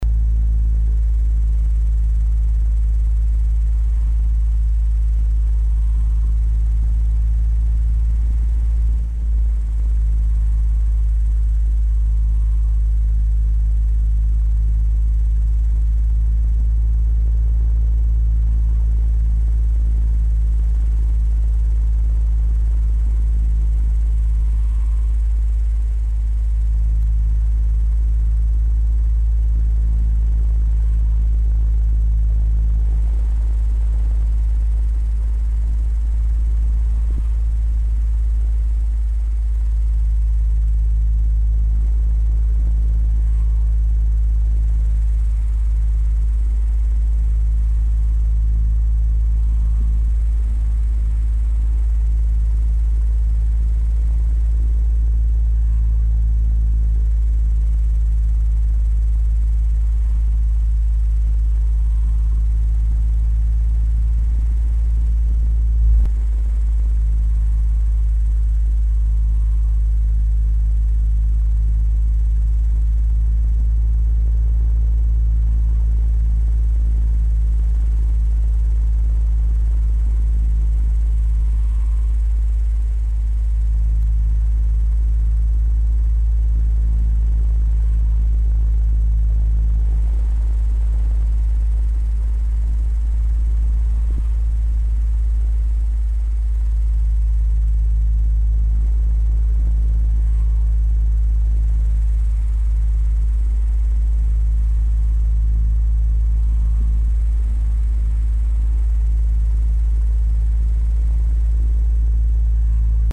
Quad [flying].mp3